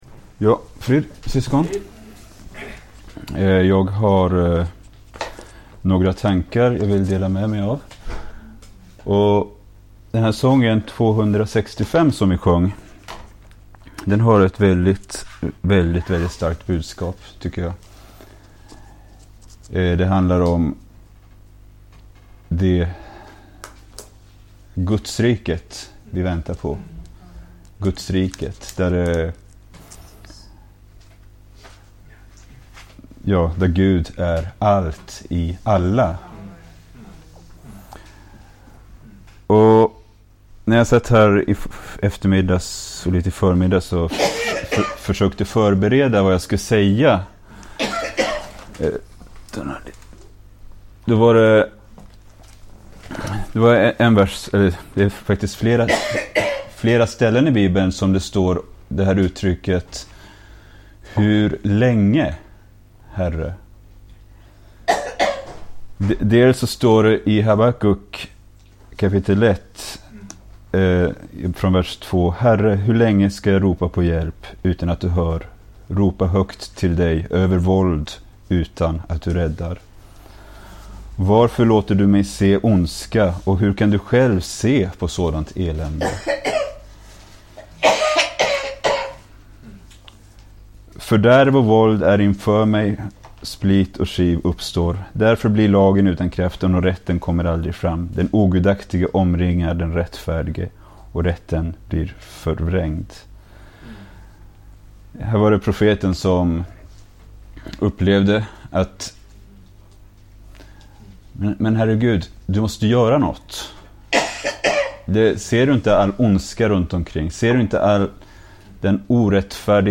Bibelstudium inspelat i Skälby 30 juli 2024.